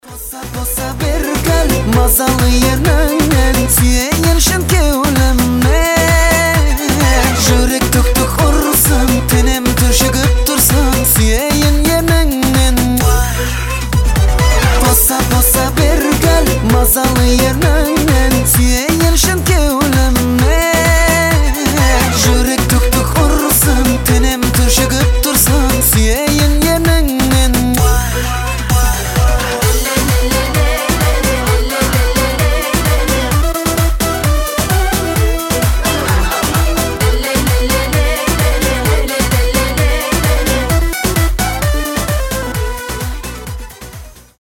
танцевальные , казахские
зажигательные